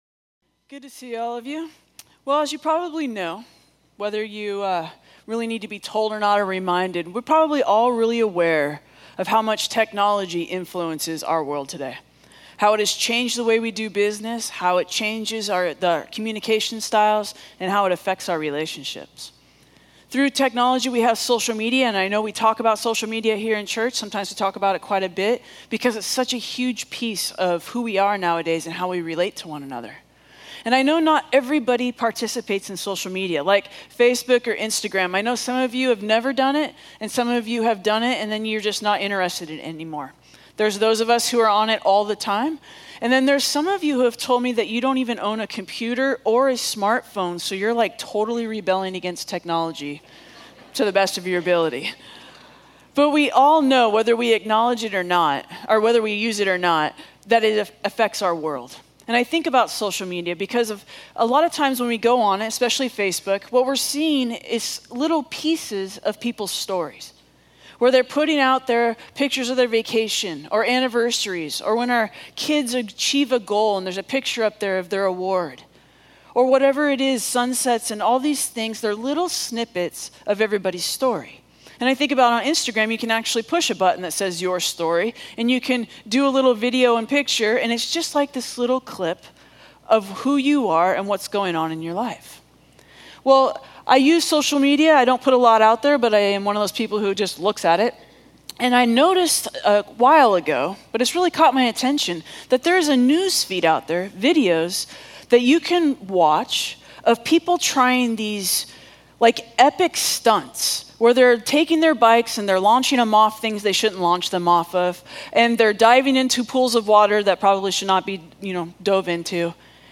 A message from the series "Beyond."